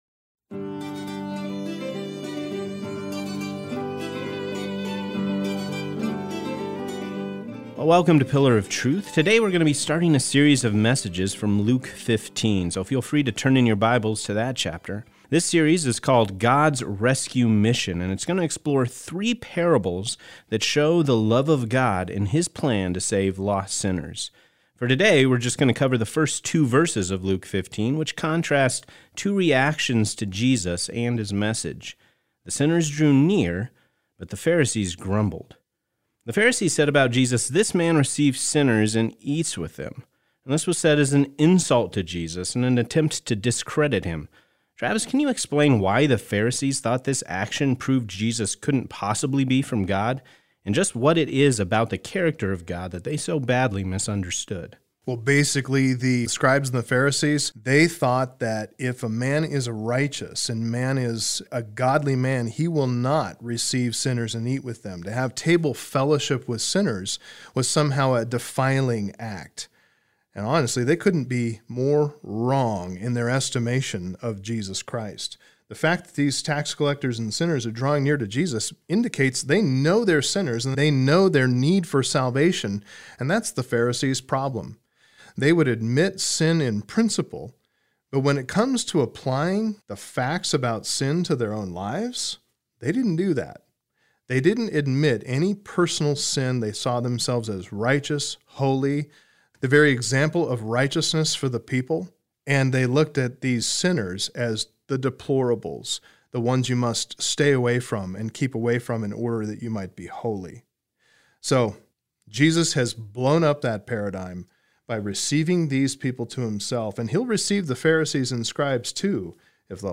Message Transcript